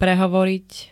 Zvukové nahrávky niektorých slov
jtjd-prehovorit.ogg